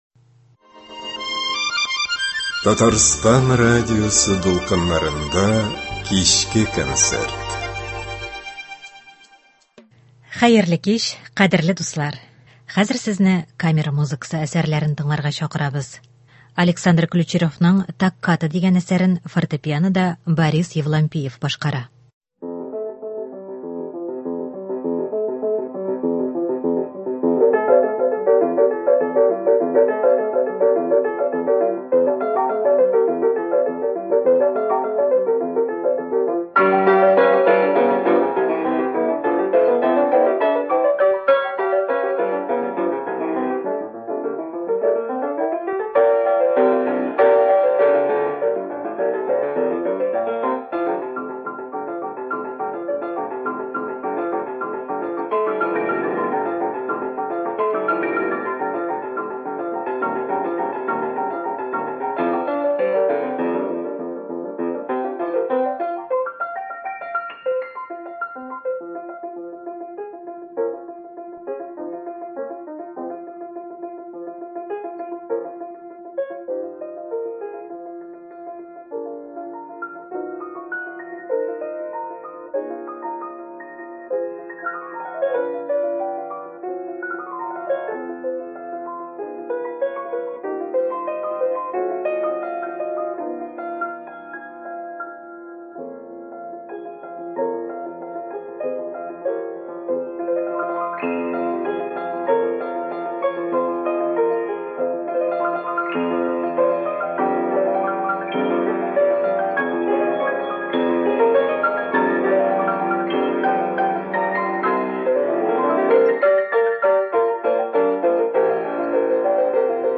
Көндезге концерт.